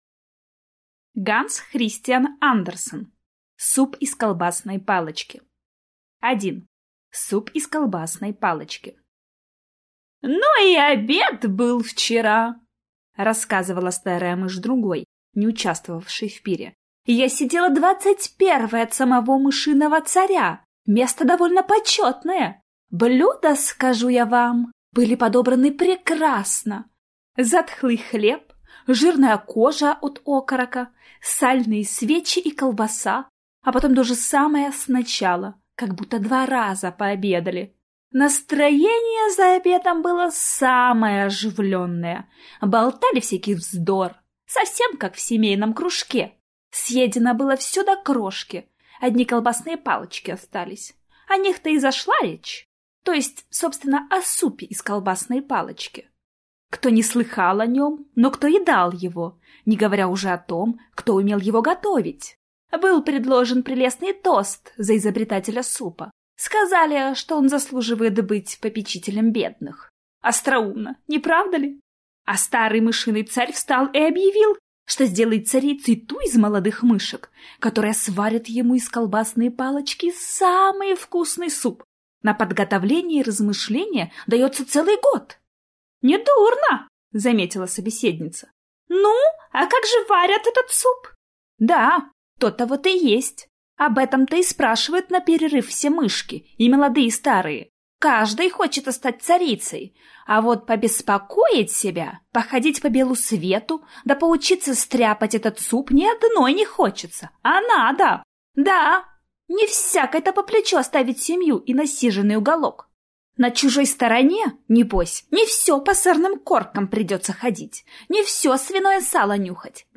Аудиокнига Суп из колбасной палочки | Библиотека аудиокниг